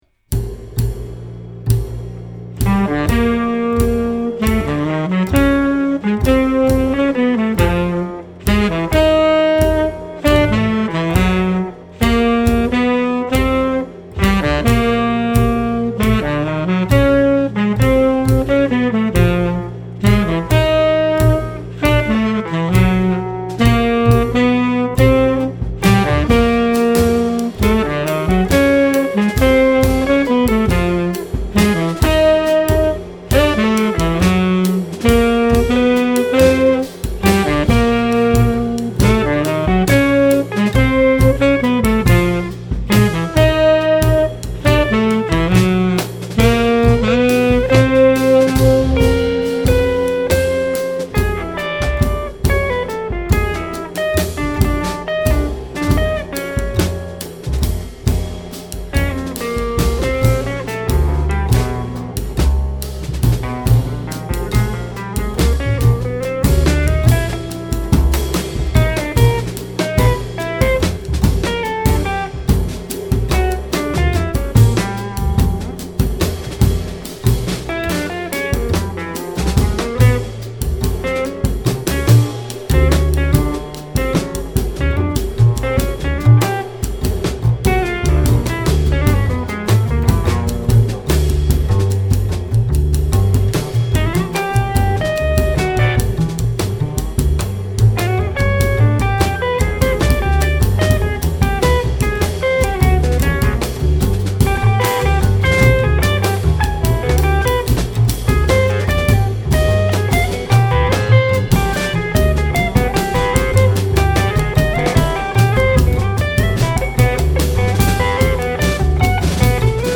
Oscillating between composition and collective improvisation
creates a raw sound dealing with density and space.